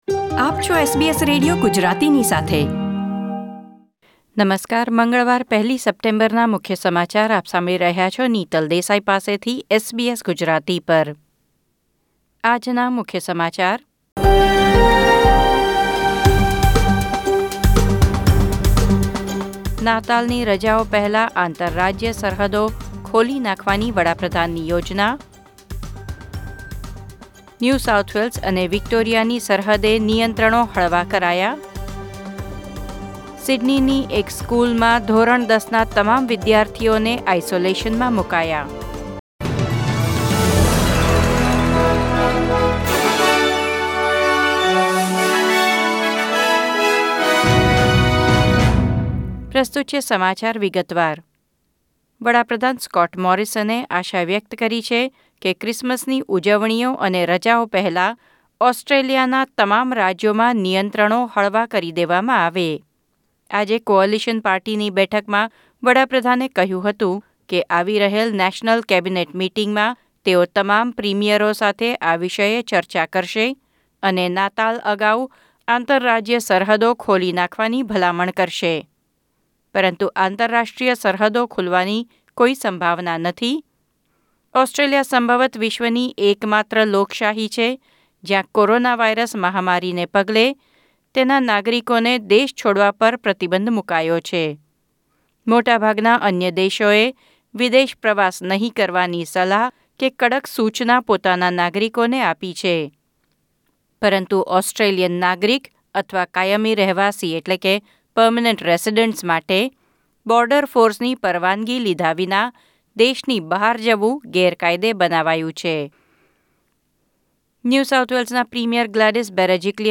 SBS Gujarati News Bulletin 1 September 2020